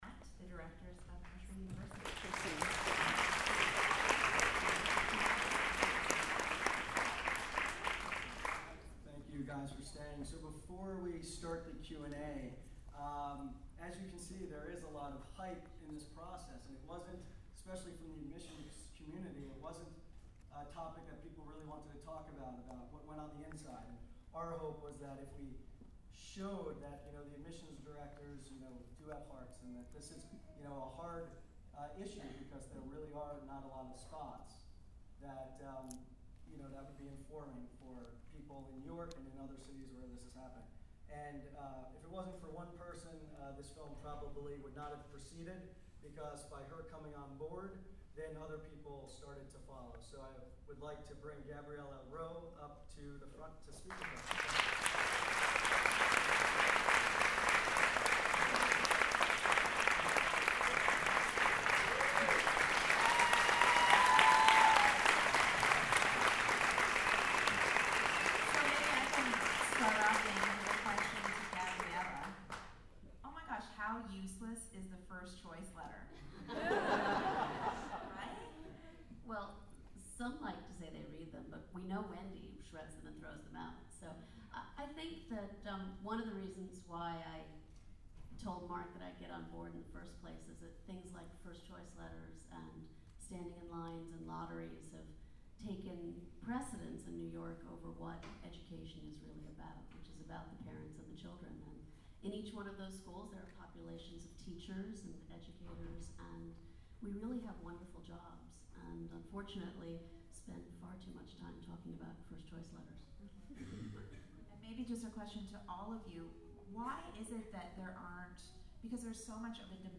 nursery_university_qa.mp3